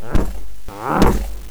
mummy_attack13.wav